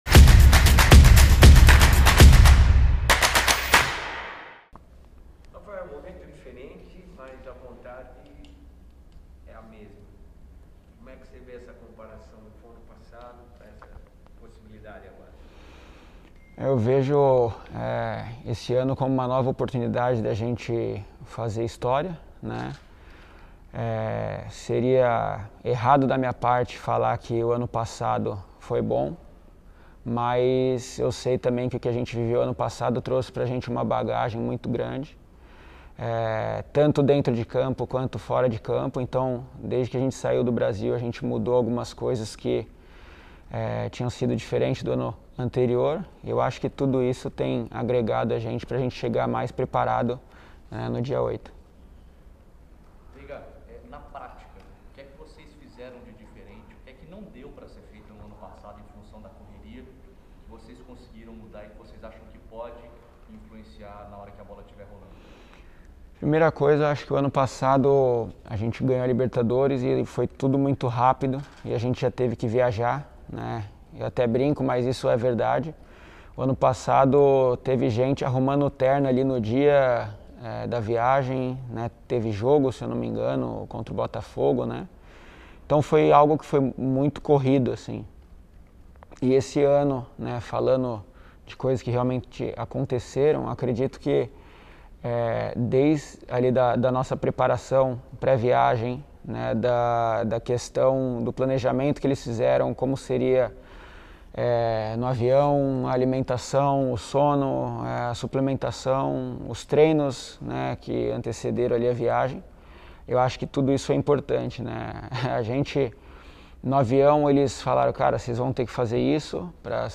Autor de um dos gols do Palmeiras na grande final da CONMEBOL Libertadores 2021, que ajudou o Verdão a se classificar para mais uma edição da Copa do Mundo de Clubes da FIFA, o meio-campista Raphael Veiga concedeu entrevista coletiva nesta sexta-feira (04), no hotel em que o time está hospedado em Abu Dhabi, nos Emirados Árabes Unidos. Artilheiro do time na temporada passada com 18 gols, o camisa 23 projetou a nova chance da equipe no torneio internacional.
COLETIVA-_-RAPHAEL-VEIGA-_-ABU-DHABI.mp3